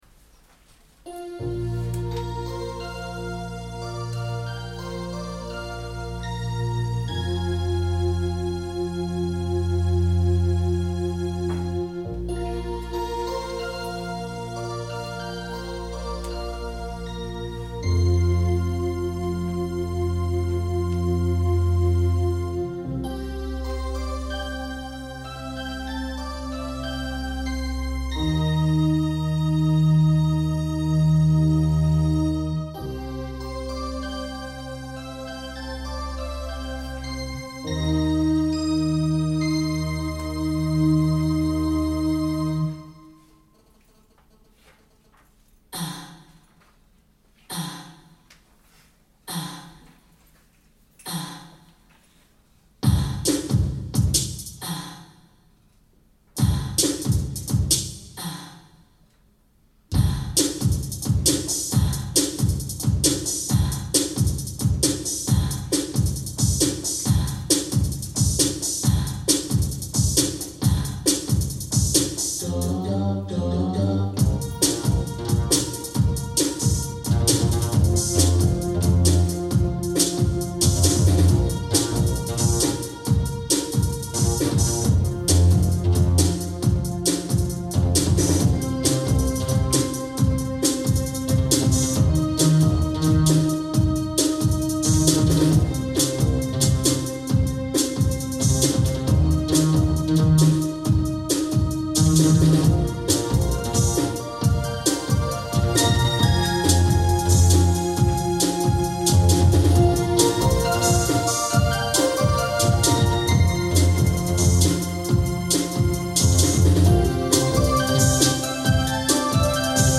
keyboard ensemble